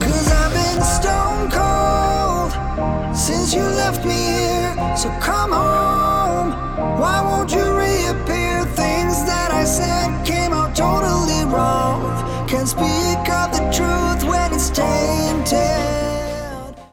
Hieronder een flink gecomprimeerde MP3 en een WAV file, zodat je het verschil kunt horen.
Het is een ongecomprimeerd bestand, het haalt dus niets ‘weg’ uit de audio om het bestand kleiner te maken.